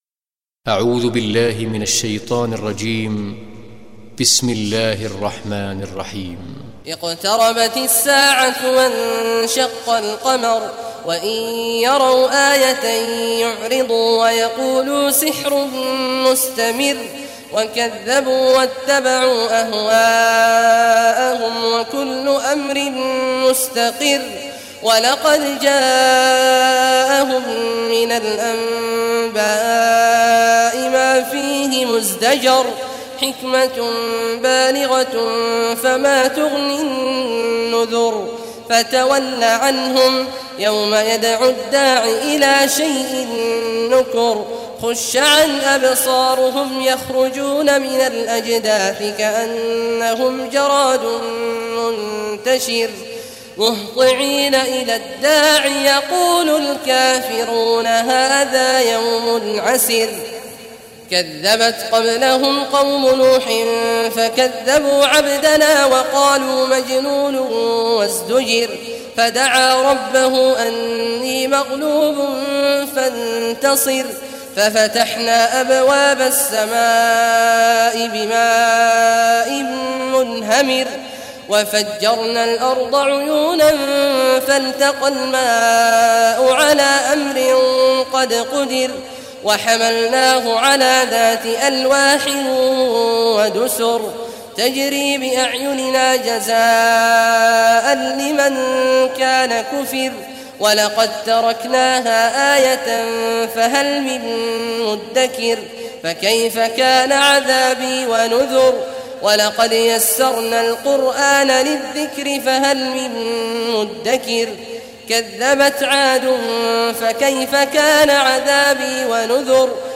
Surah Al-Qamar Recitation by Sheikh Al Juhany
Surah Al-Qamar, listen or play online mp3 tilawat / recitation in Arabic in the beautiful voice of Sheikh Abdullah Awad al Juhany.